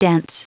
Prononciation du mot dense en anglais (fichier audio)
Prononciation du mot : dense